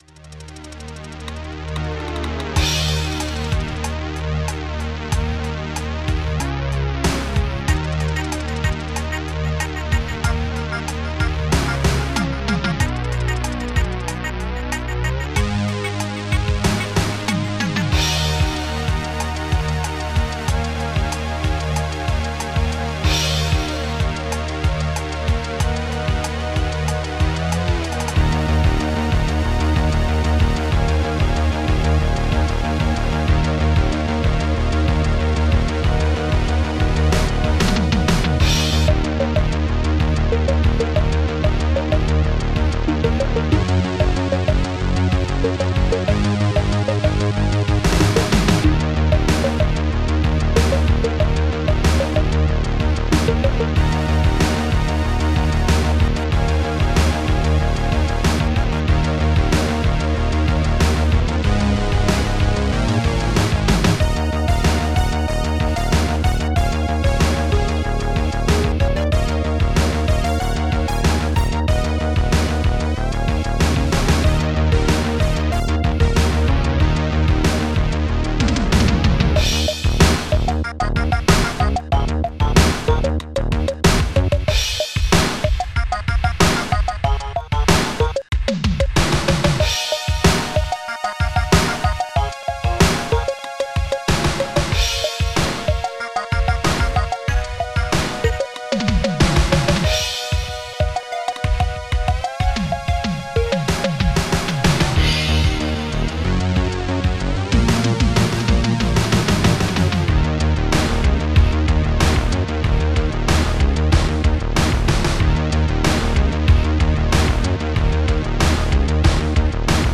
synthtom
synthlead1
cymbal2
Breakclap